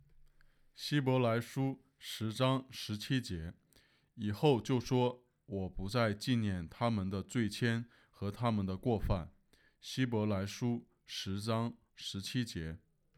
经文背诵